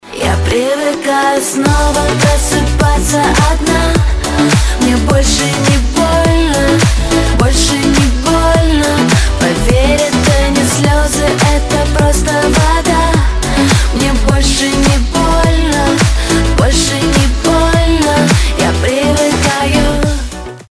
• Качество: 256, Stereo
поп
танцевальные
vocal